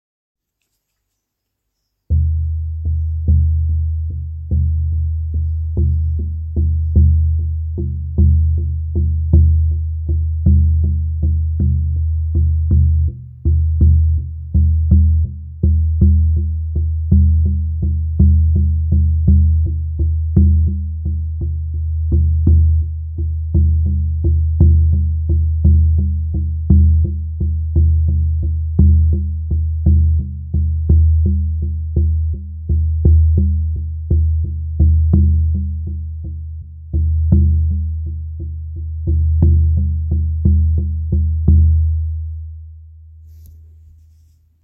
Hij is 42 cm doorsnee met een frame van Es.
Voelt stabiel en beweegt zich in het frame, wederom een diepere klank.
Een paard met een flinke stevige galop.